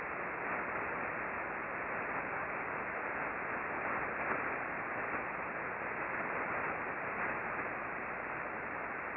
We used the Icom R-75 HF Receiver tuned to 20.408 MHz (LSB).
Click here for a 9 second recording of the bursting at 1042